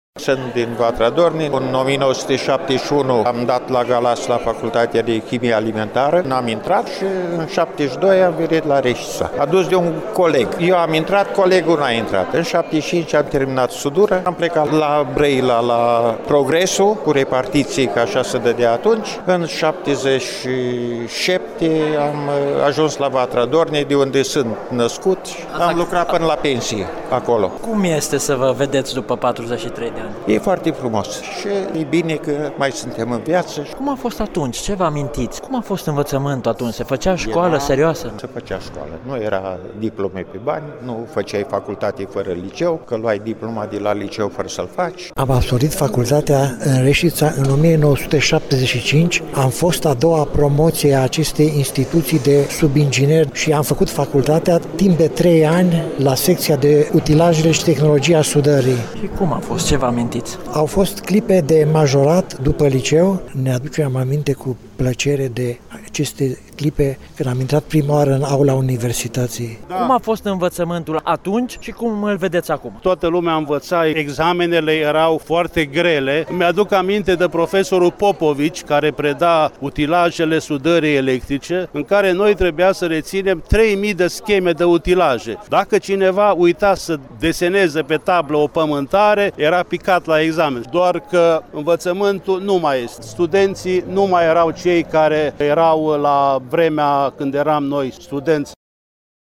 [FOTO] Revedere după 43 de ani: Absolvenţii promoţiei 1975 ai fostului Institut de Subingineri s-au reîntâlnit pentru prima dată la Reşiţa
a stat de vorbă cu unii dintre ei: